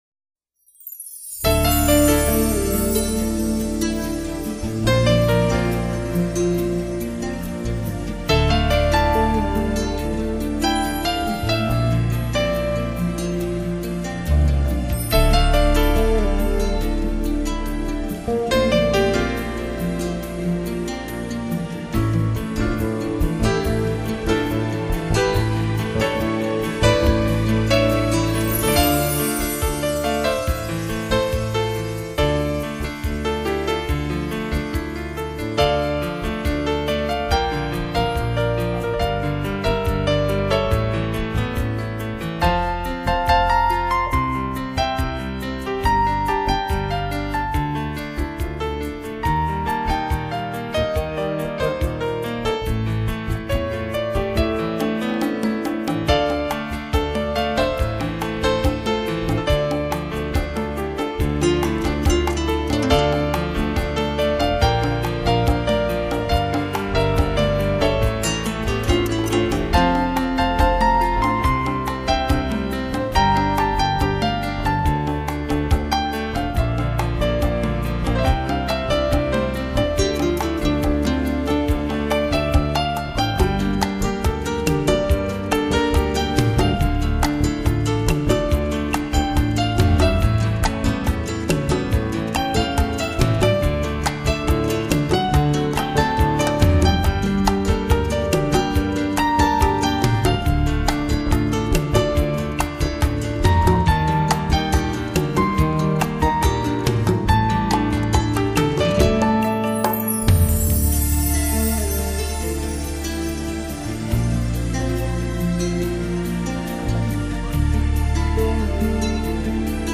一片由吉他和钢琴为主乐器，
以柔慢而稳定的节奏来演绎的浪漫，
轻缓却没有丝毫拖沓和迷废的音乐空间！
现在更提升了电子键盘的作用来连贯主体！